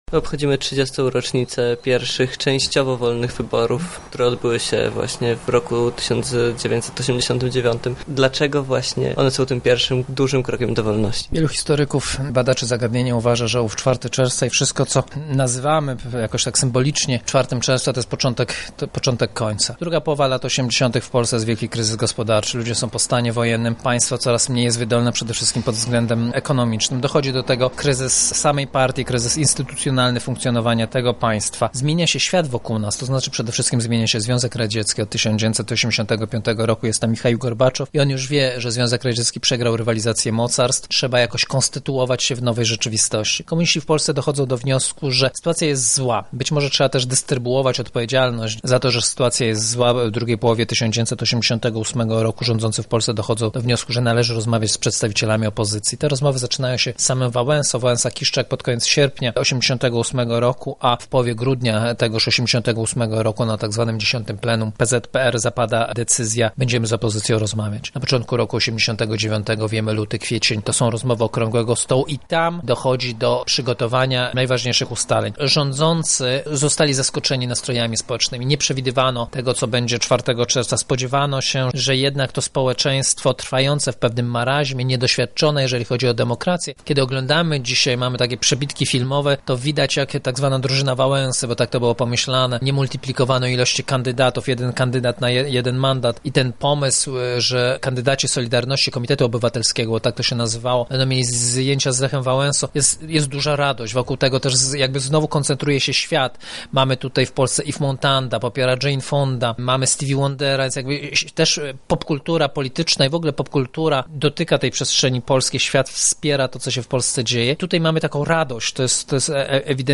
w rozmowie z naszym reporterem.